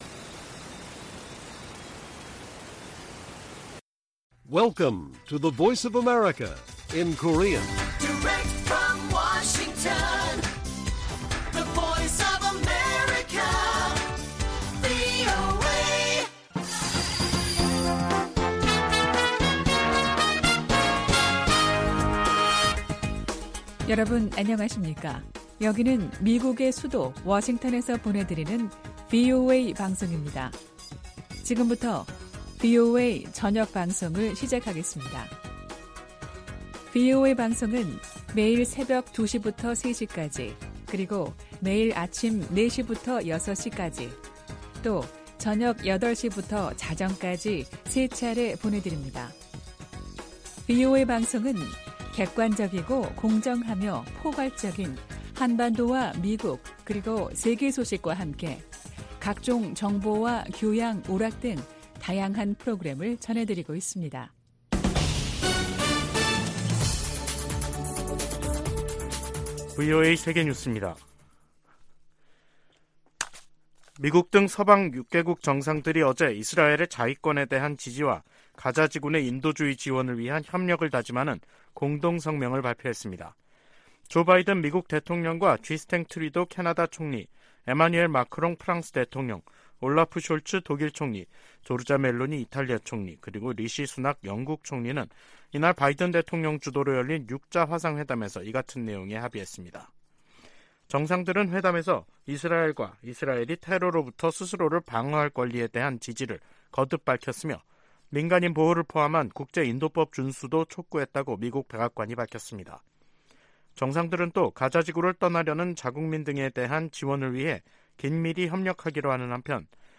VOA 한국어 간판 뉴스 프로그램 '뉴스 투데이', 2023년 10월 23일 1부 방송입니다. 미국 백악관은 북한에서 군사 장비를 조달하려는 러시아의 시도를 계속 식별하고 폭로할 것이라고 강조했습니다. 미국 정부가 북한과 러시아의 무기 거래 현장으로 지목한 라진항에 또다시 컨테이너 더미가 자리했습니다.